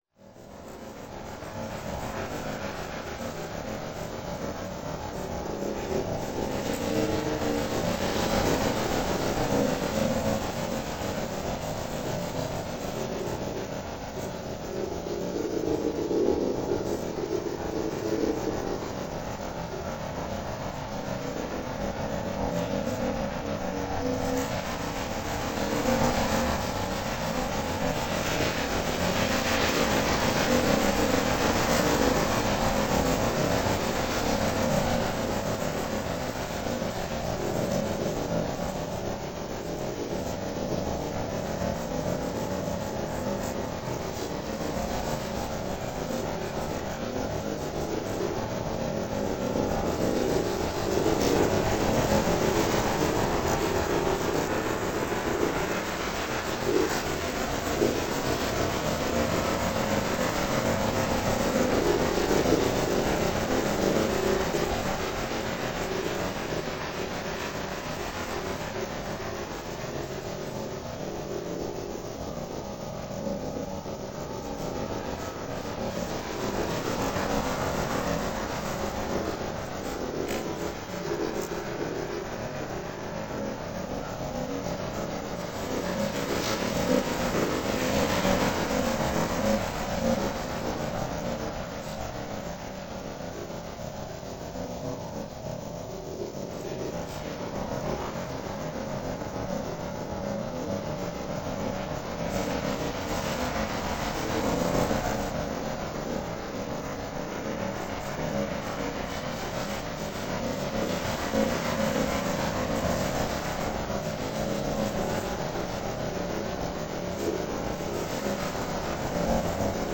A remix
our field recording of waves from Hayling Island, April 2014.